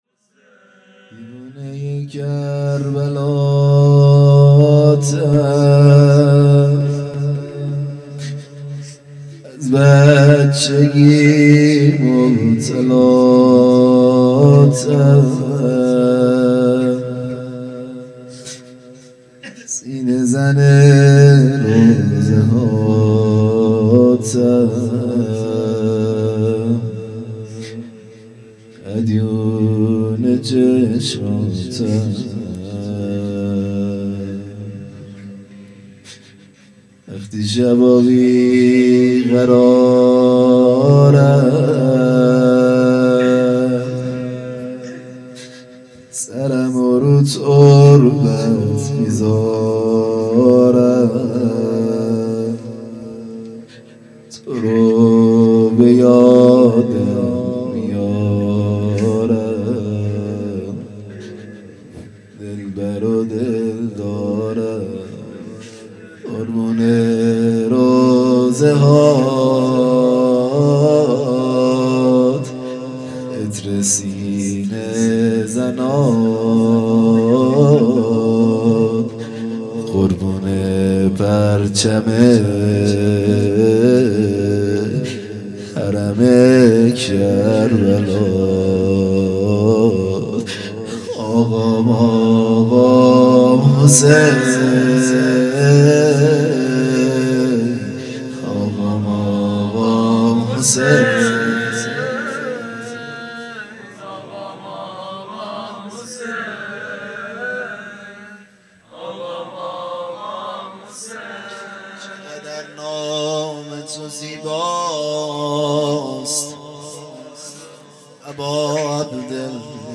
خیمه گاه - هیئت بچه های فاطمه (س) - مناجات پایانی | زائر کربلاتم
جلسه هفتگی